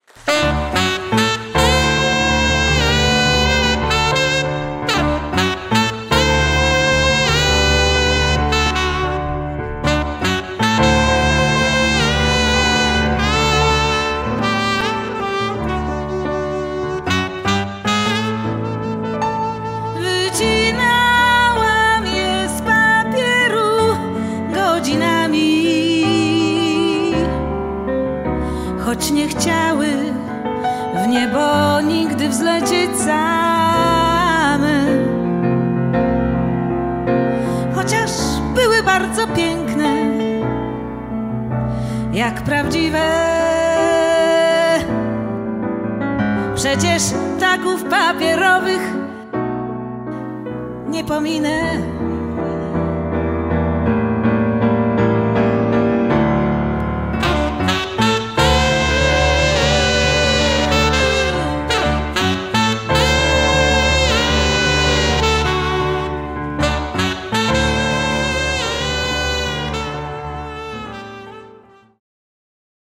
Genre: Jazz, Funk / Soul
Grand Piano
Saxophone
Vocals